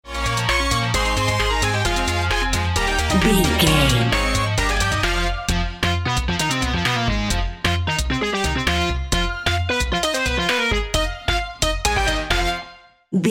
Aeolian/Minor
Fast
bouncy
cheerful/happy
funky
groovy
lively
mechanical
playful
uplifting
synthesiser
drum machine